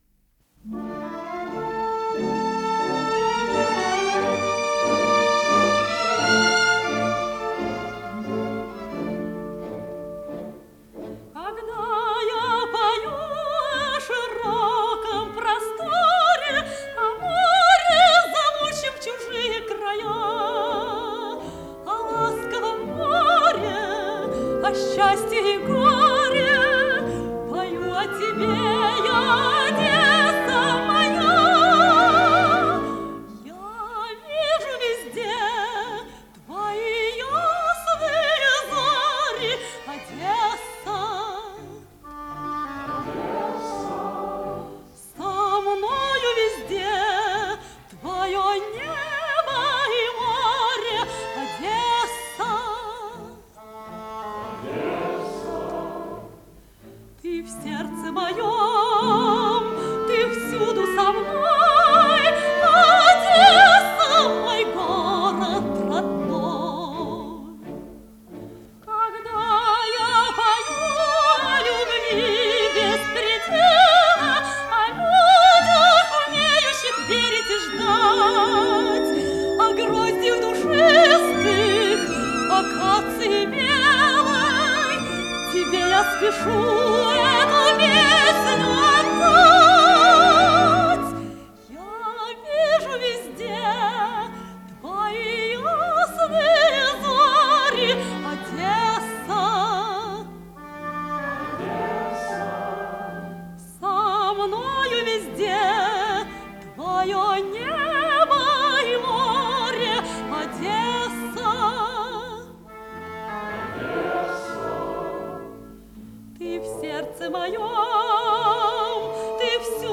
с профессиональной магнитной ленты
Скорость ленты76 см/с